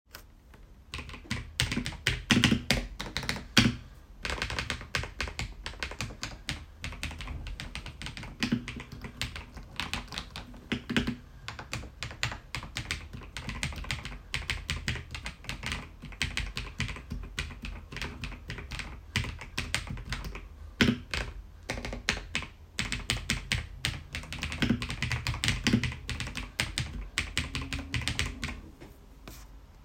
Przełączniki Lion (liniowe, smarowane fabrycznie)
Dźwięk jest zrównoważony — nie jest ani przesadnie głośny, ani zbyt cichy. Zadbano o odpowiednie wygłuszenie konstrukcji, co przekłada się na przyjemne brzmienie podczas pisania, które nie powinno przeszkadzać nikomu w naszym otoczeniu.
redragon-flekact-pro-recenzja-brzmienie.mp3